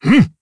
Dakaris-Vox_Attack2_jp.wav